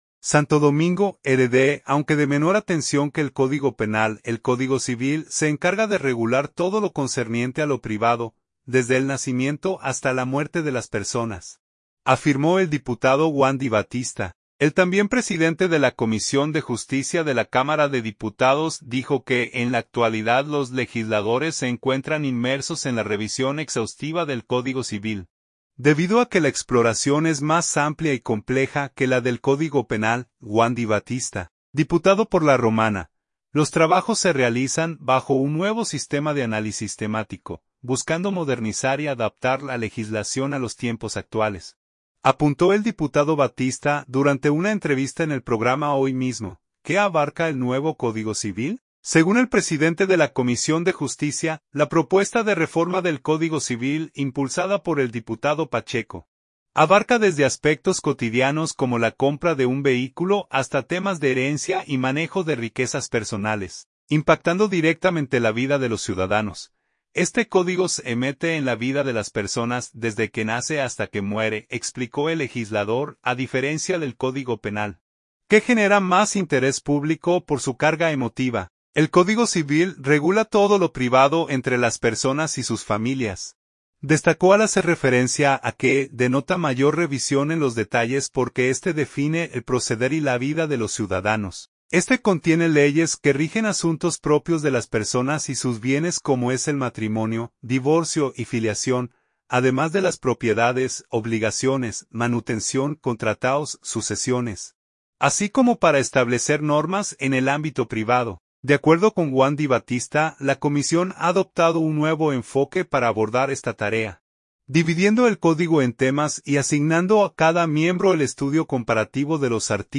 “Los trabajos se realizan bajo un nuevo sistema de análisis temático, buscando modernizar y adaptar la legislación a los tiempos actuales”, apuntó el diputado Batista durante una entrevista en el programa "Hoy Mismo".